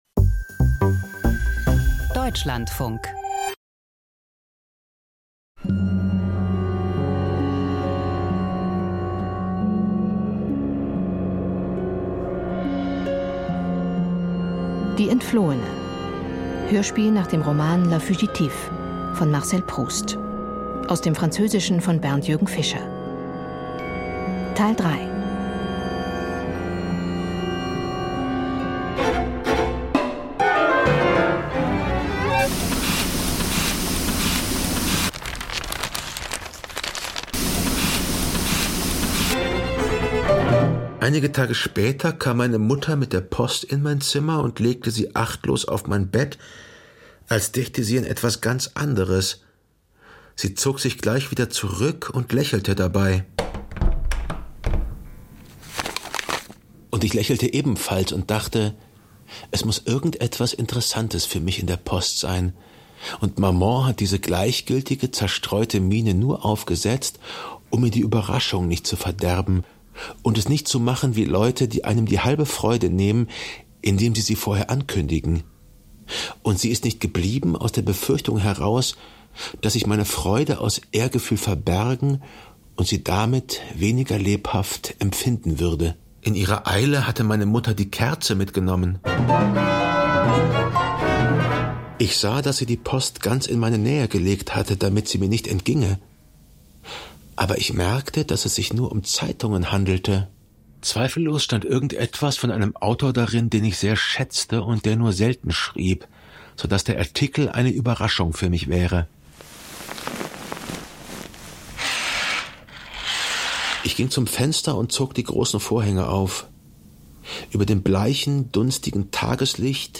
Hörspiel nach Marcel Proust - Die Entflohene (3/4)